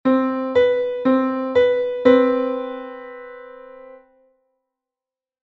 The Major 7th is one half-step smaller than an octave and is comprised of five whole steps and one half-step.
major-7.mp3